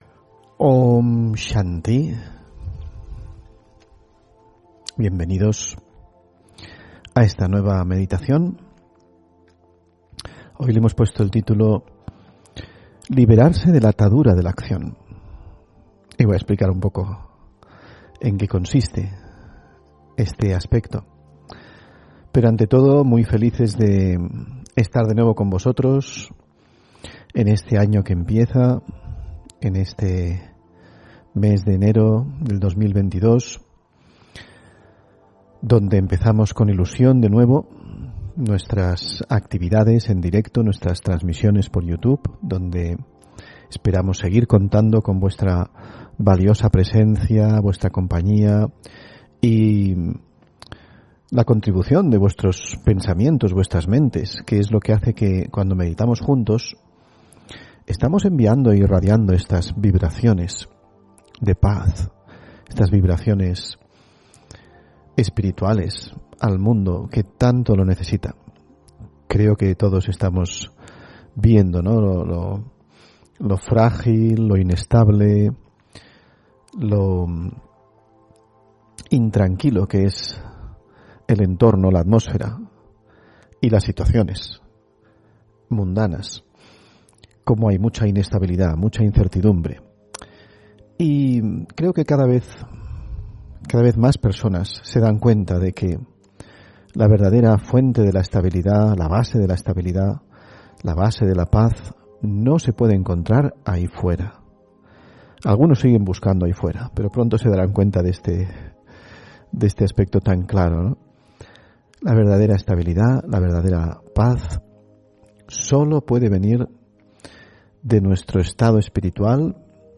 Meditación y conferencia: Liberarse de la atadura de la acción (4 Enero 2022)